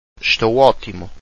Eshtoh ohtimu/a – note that the ‘p’ in ‘optimo’ (fine) is virtually silent (the Brazilian spelling, without a ‘p’, was adopted in the Portuguese orthographic agreement, so technically it should be omitted when writing).